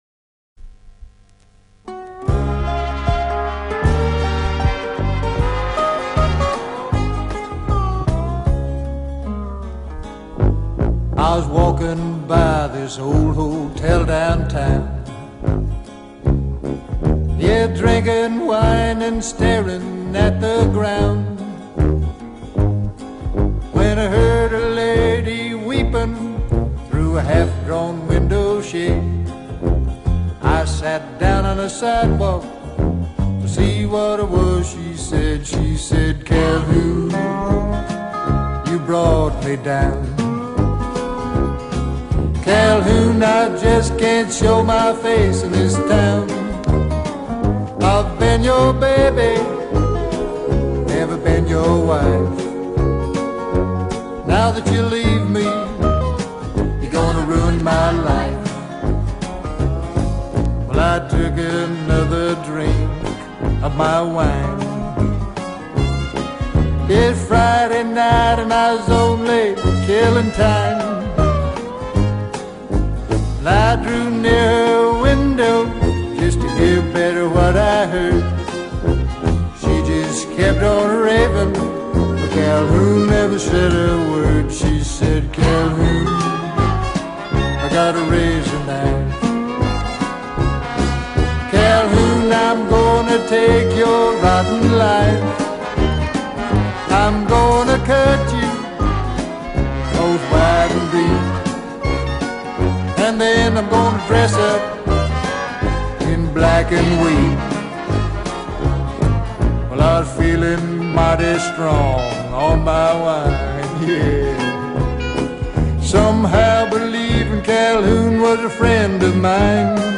композитором и весьма неплохим исполнителем кантри-песен.